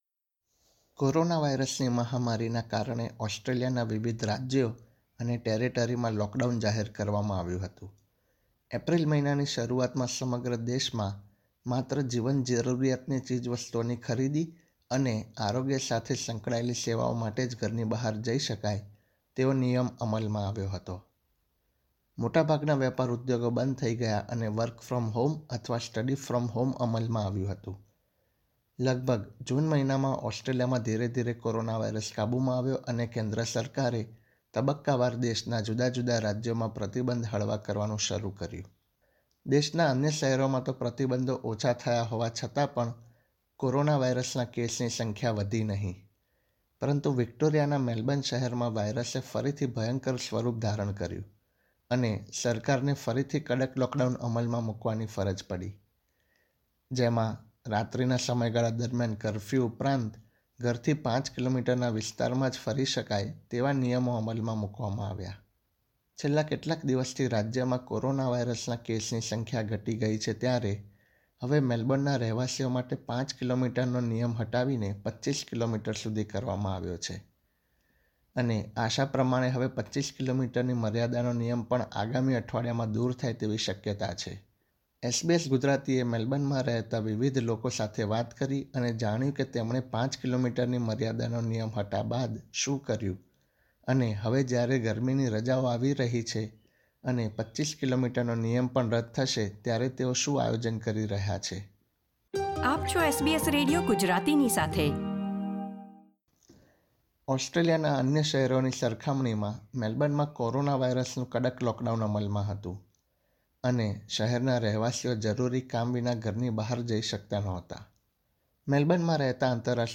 મેલ્બર્નમાં ઘરથી 5 કિલોમીટરના અંતરમાં જ મુસાફરી કરી શકાય તે નિયમ ઉઠાવી લીધા બાદ હવે વધુ નિયંત્રણો હળવા થવા જઇ રહ્યા છે ત્યારે શહેરના રહેવાસીઓ કેવા આયોજનો કરી રહ્યા છે તે વિશે તેમણે SBS Gujarati સાથે વાત કરી હતી.